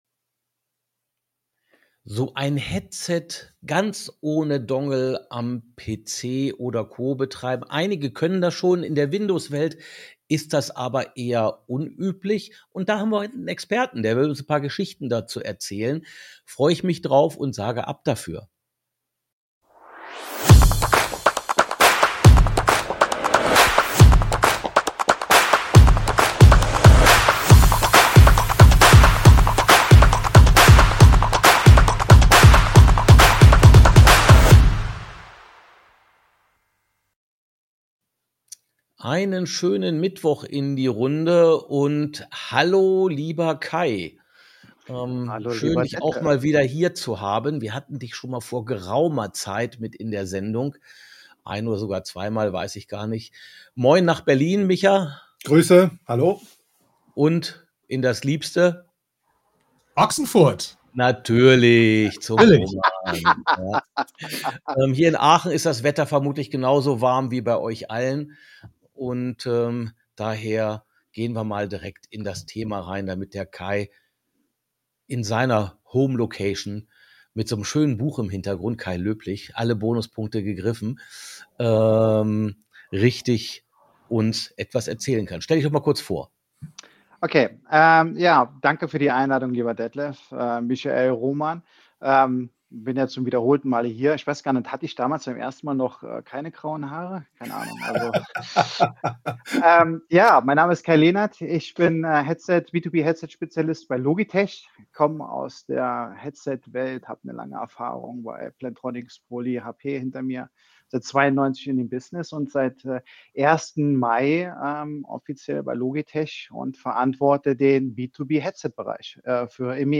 wie immer im LiveStream am Mittwoch, pünktlich um 12:29 Uhr.